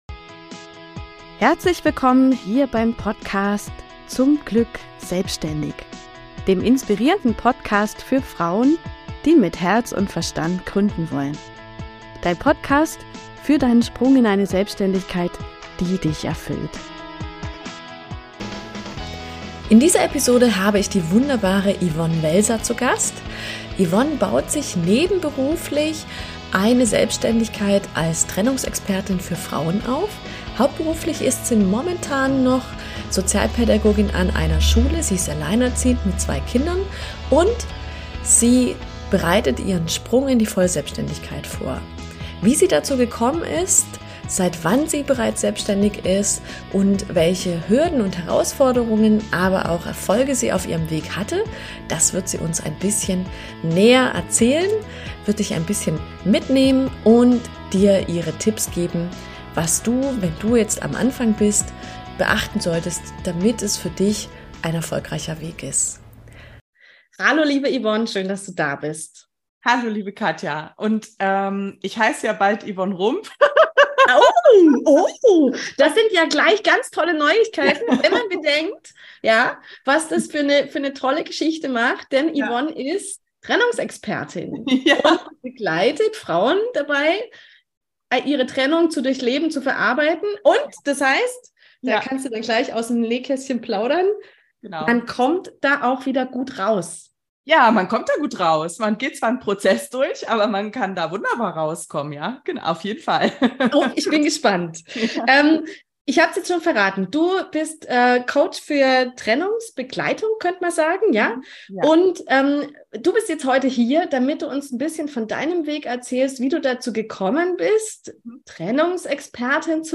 Freu dich auf ein ehrliches und spannendes Interview!